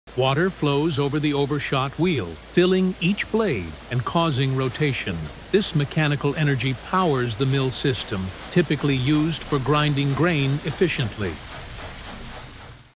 Flowing water turns an overshot